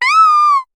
Cri de Nounourson dans Pokémon HOME.